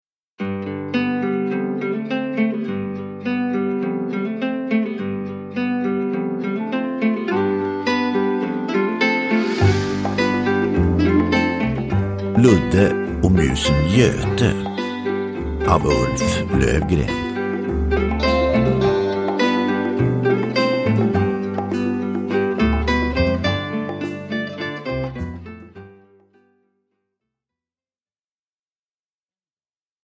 Ludde och musen Göte – Ljudbok – Laddas ner